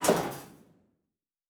Metal Foley 2.wav